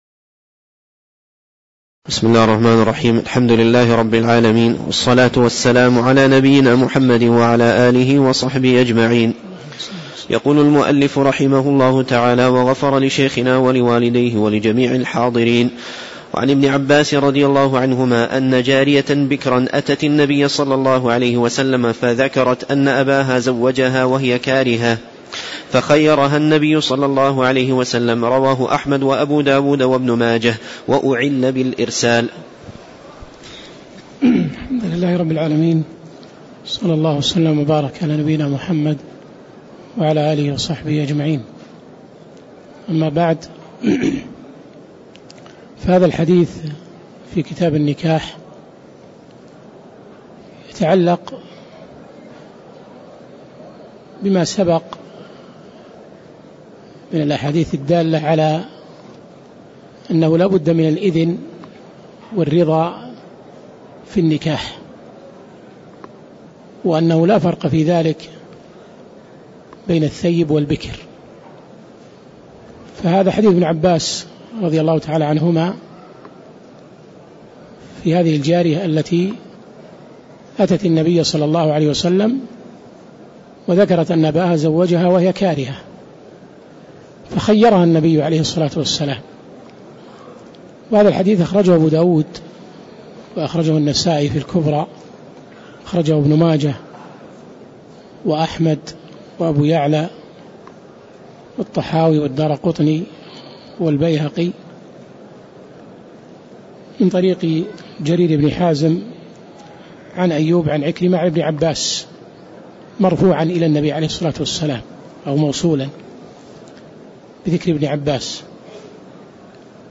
تاريخ النشر ١١ ربيع الأول ١٤٣٧ هـ المكان: المسجد النبوي الشيخ